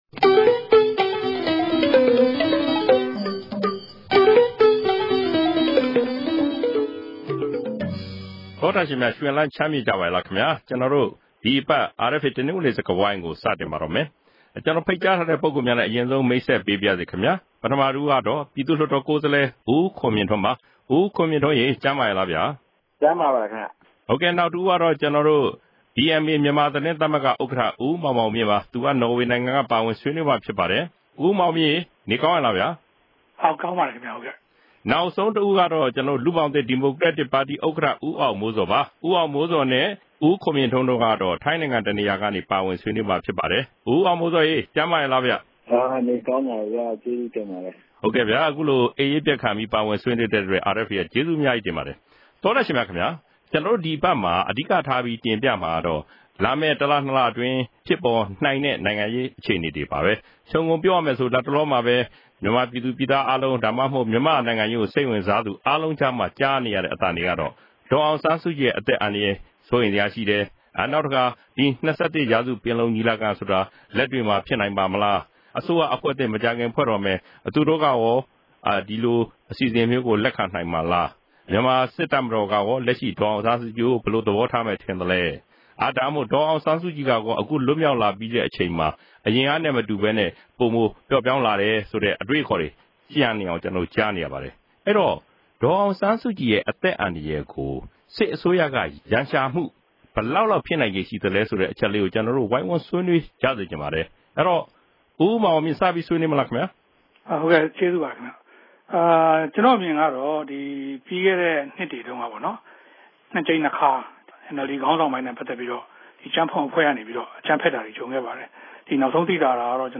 တနင်္ဂနွေစကားဝိုင်း။